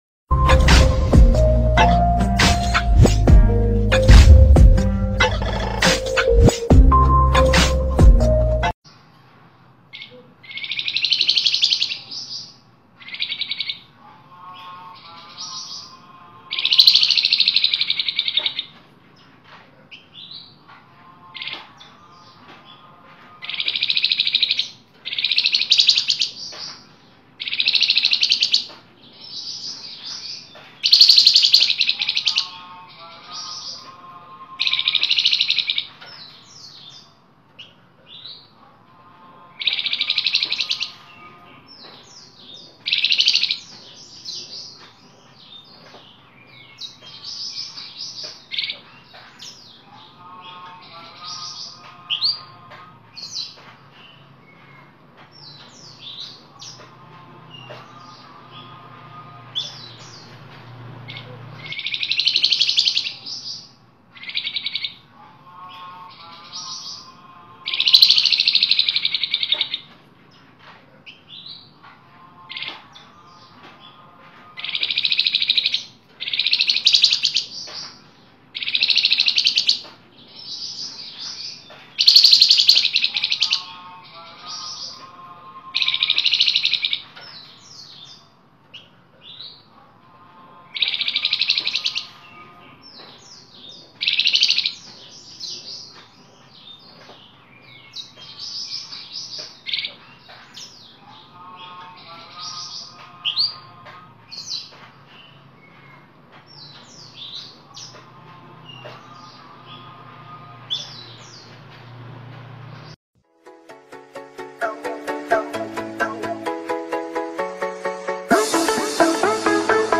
Suara Ngebren Rapat Burung Flamboyan|